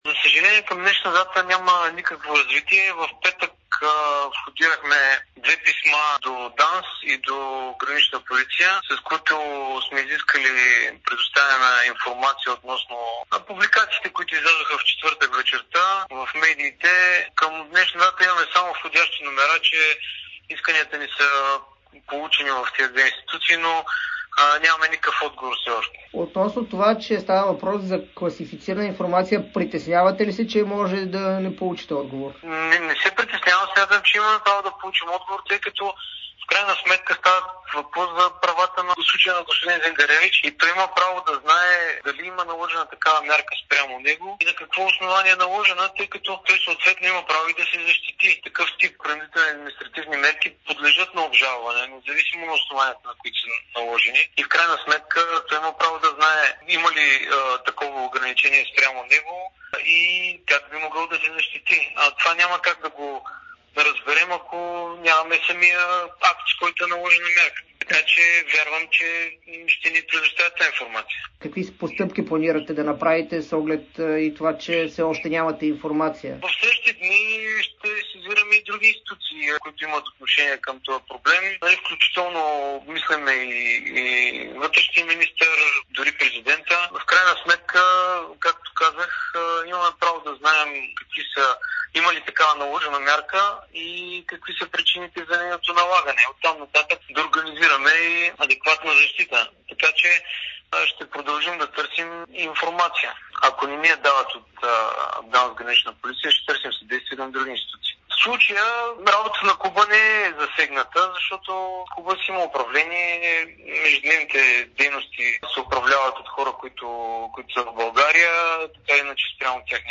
говори ексклузивно пред Дарик радио и dsport